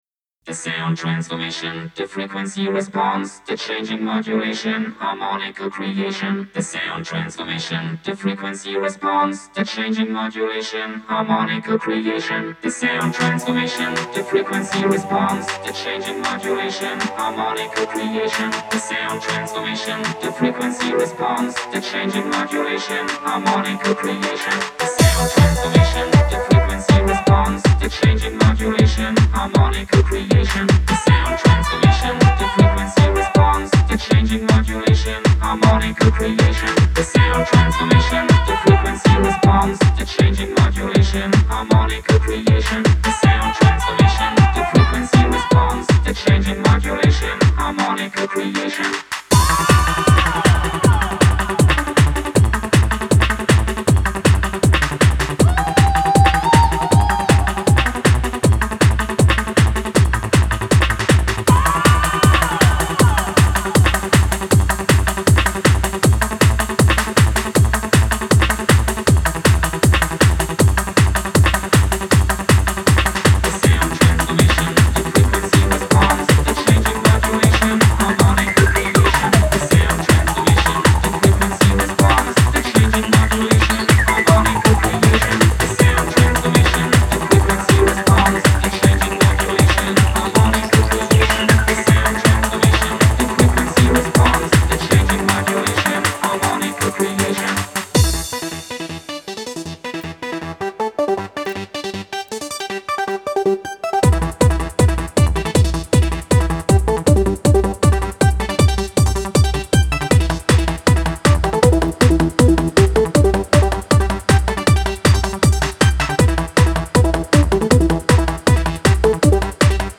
Genre: Hard Trance, Techno, Rave, Hardcore, Dance.